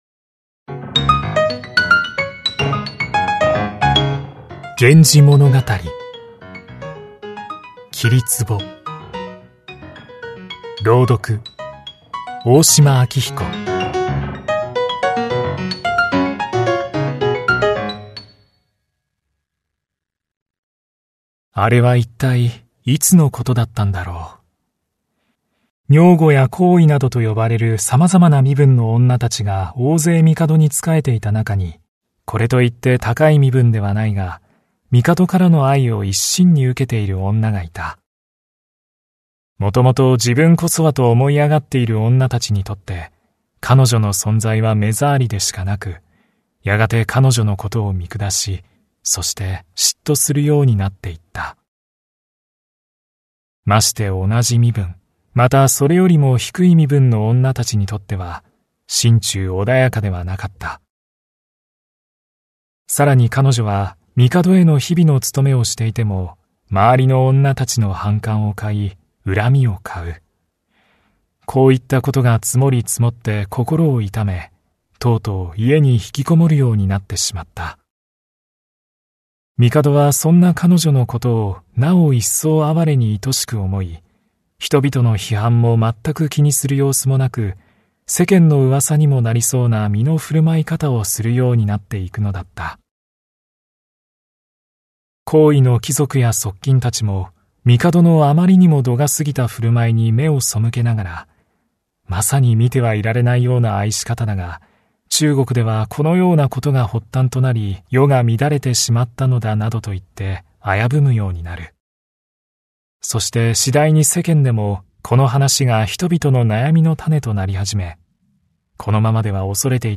[オーディオブック] 新現代語訳 源氏物語 01 桐壺
そして、その当時の朗読の仕方はといえば、おそらく静かに「物語る」ようにして朗読されていたんだと僕は思う。